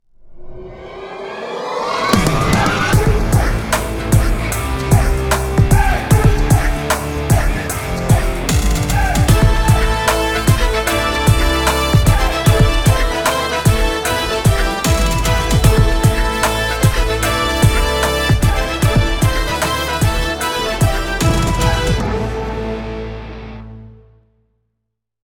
We enhanced the sounding of the beat.